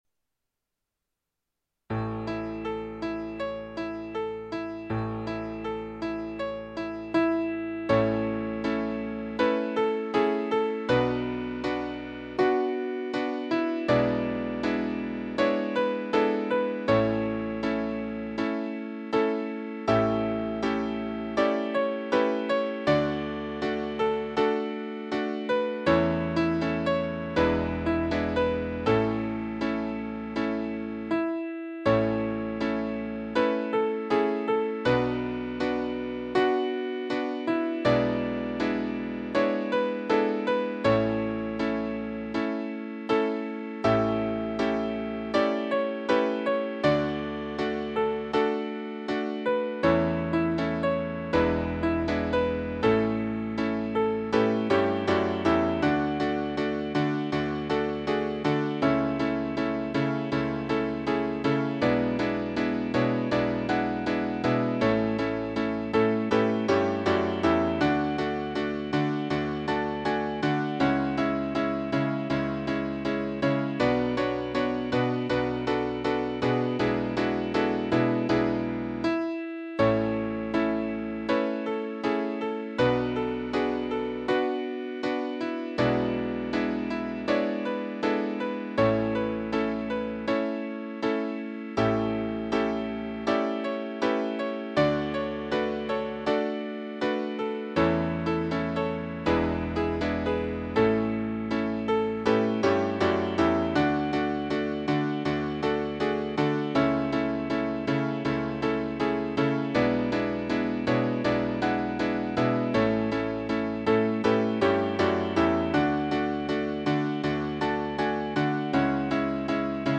ハーモニカの伴奏曲
結局、三部合奏という形でまとめ上げることにした。
使用ハーモニカは「Ａ調」。